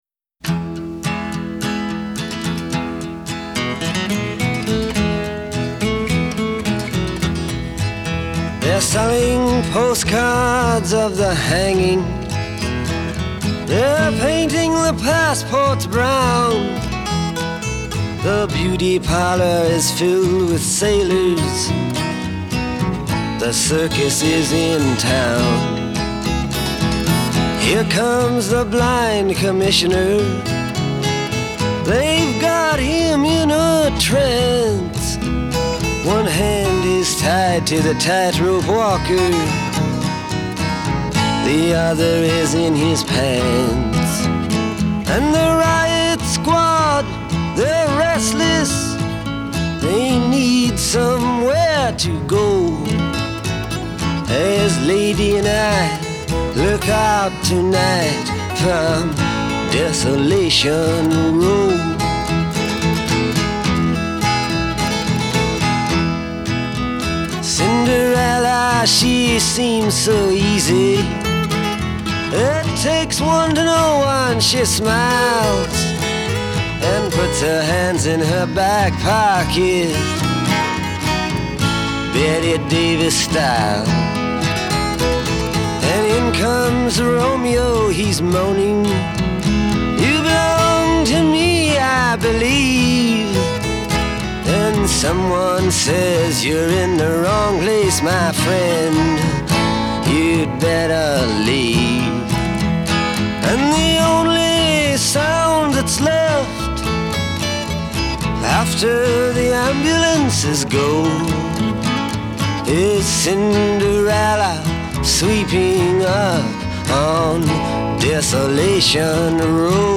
Жанр: Classic Rock